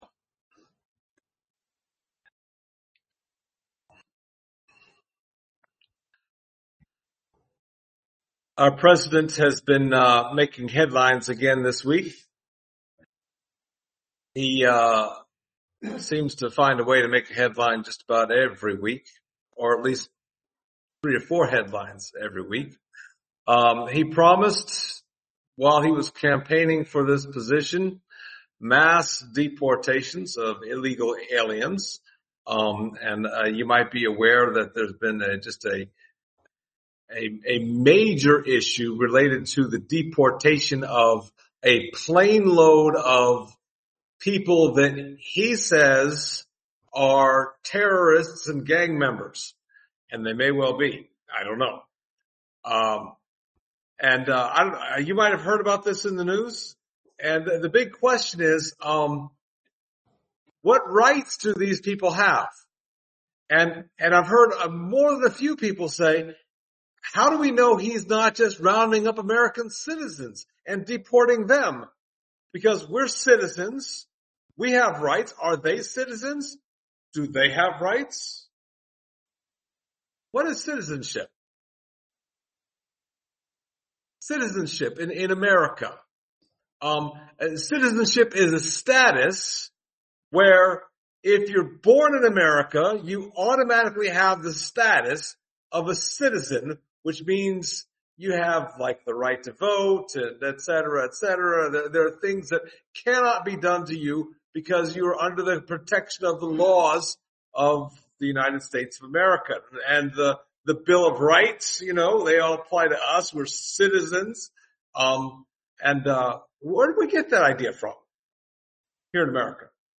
Service Type: Sunday Morning Topics: citizenship , civil disobedience , rights